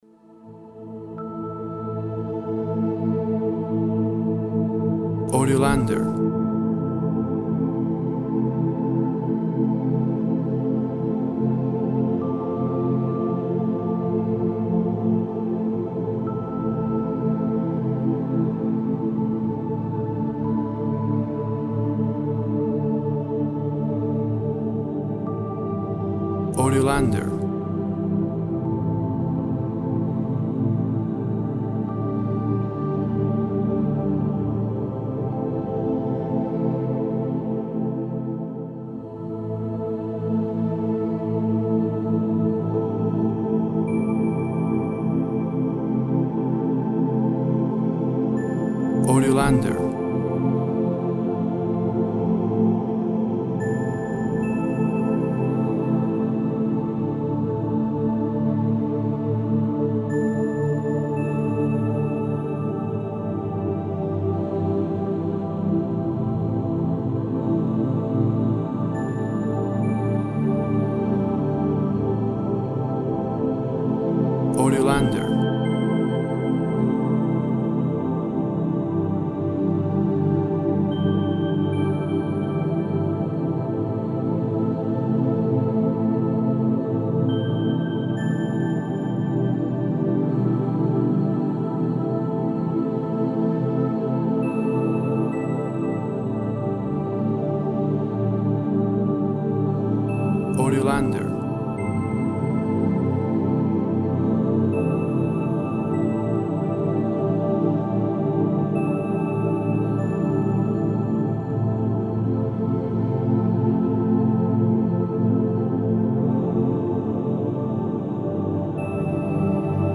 Soft persistent and hypnotic synth sounds.
Tempo (BPM) 54